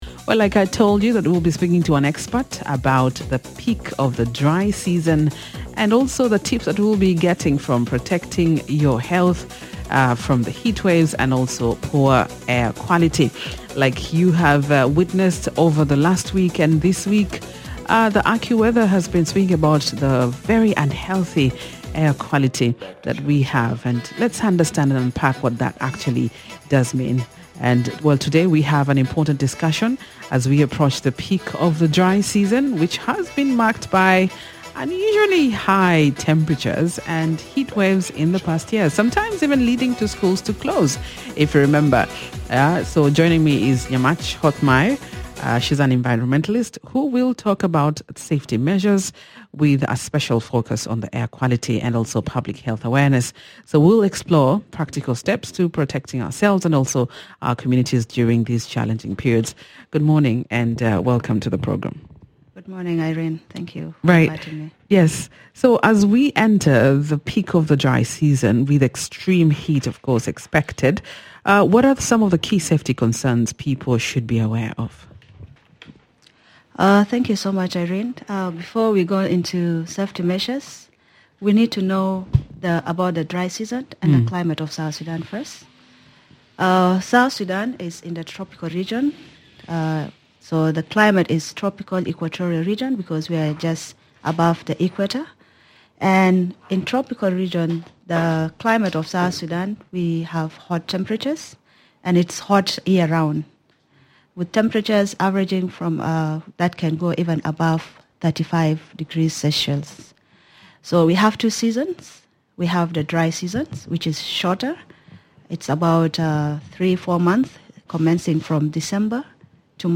We focus on South Sudan’s worsening air quality and rising heat levels as the country enters the peak of the dry season. The expert explains the causes, health impacts, and the practical steps communities can take to protect themselves. The conversation also highlights the role of authorities in enforcing environmental policies and improving public awareness.